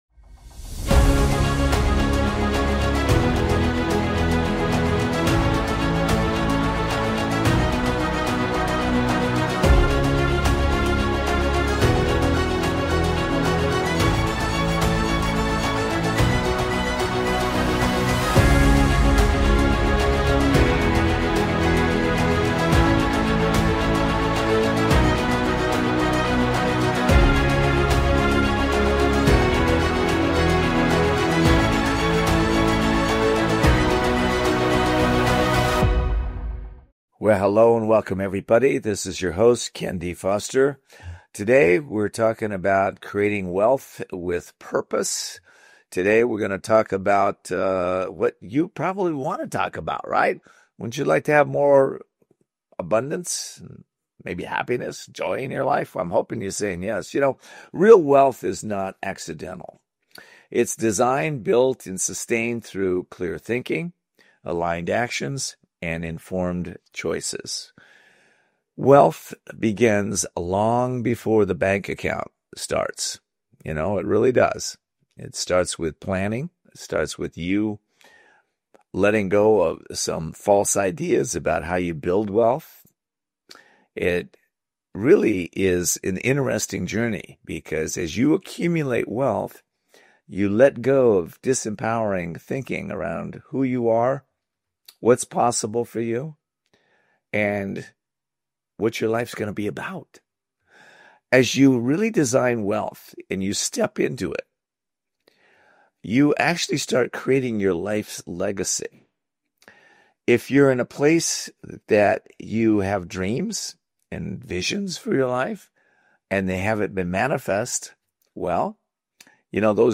This episode features Sharon Lechter, renowned entrepreneur, bestselling author, and global financial literacy advocate. Sharon reflects on the early experiences that shaped her passion for education, her 35‑year career as a CPA, and her mission to equip families and young people with practical financial tools through Pay Your Family First.